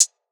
Closed Hats
pbs - thick [ Hihat ].wav